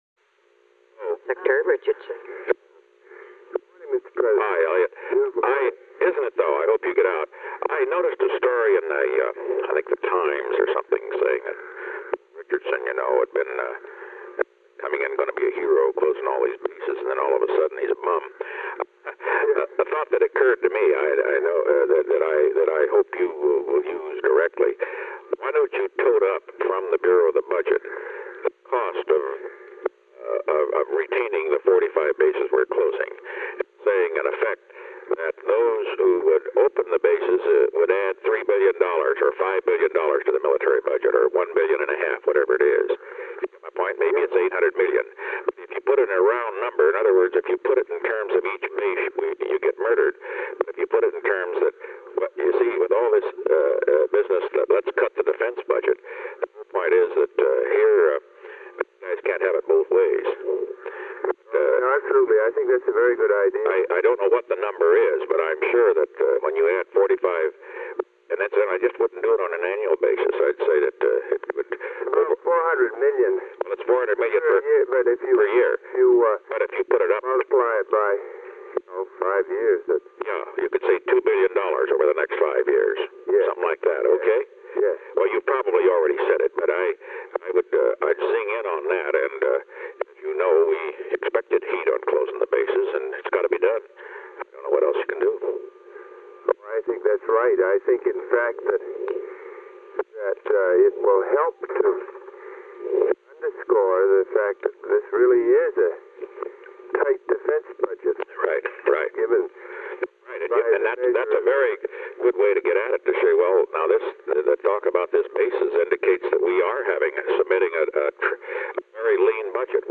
Secret White House Tapes
Location: White House Telephone
The President talked with Elliot L. Richardson.